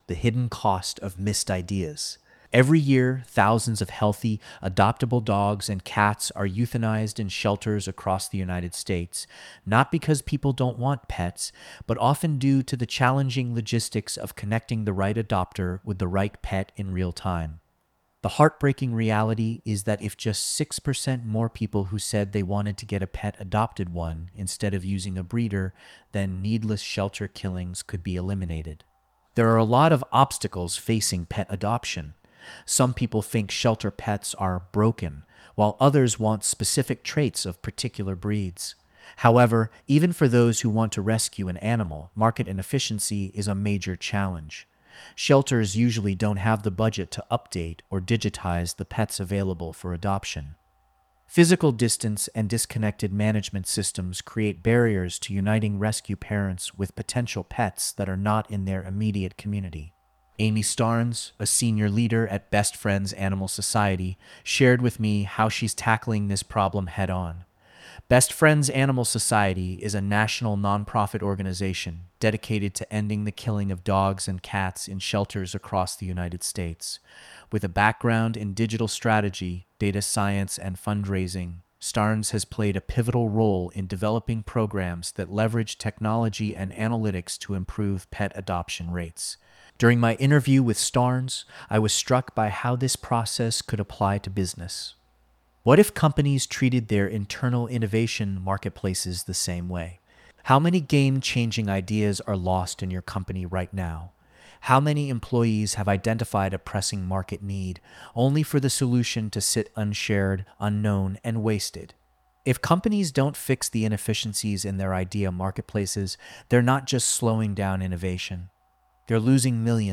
This audio was recorded by AI.